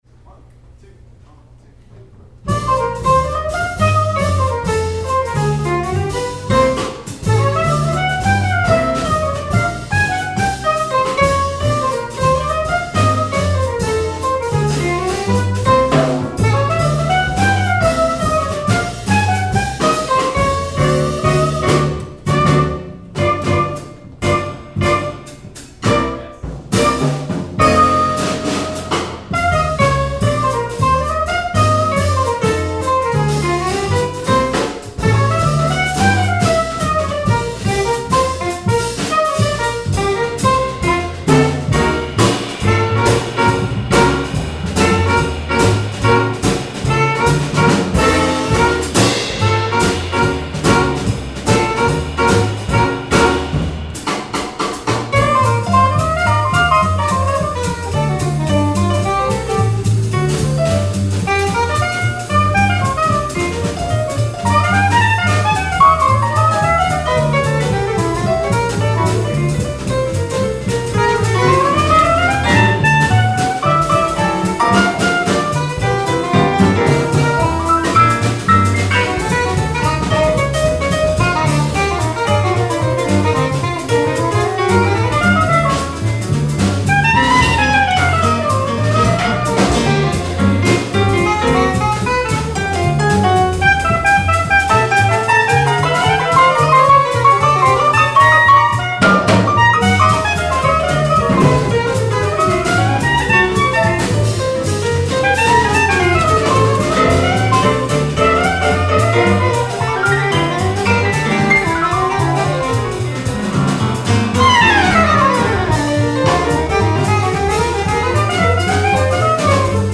Saxophone
PIano
Bass
Drums